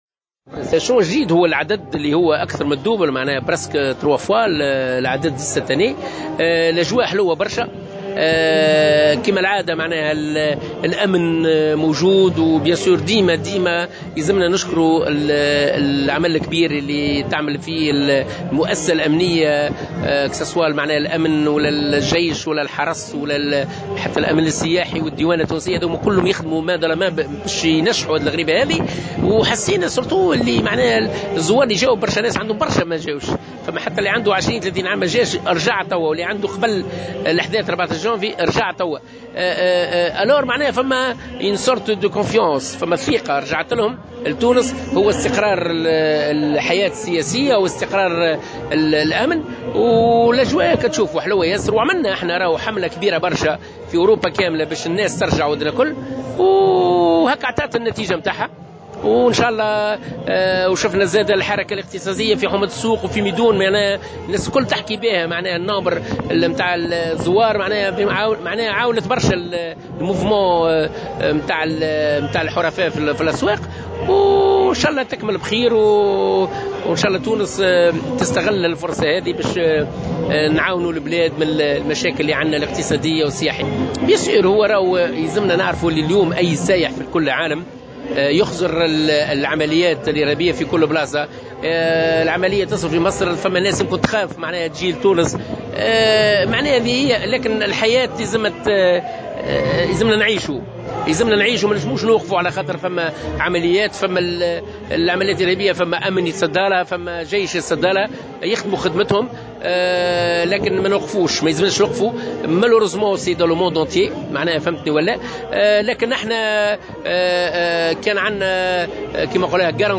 ووصف روني الطرابلسي منظّم رحلات الغريبة في تصريح للجوهرة أف أم، الأجواء بالجيدة جدا، وسط توقعات ببلوغ عدد الزوار لثلاثة أضعاف ما تم تسجيله خلال السنة الماضية.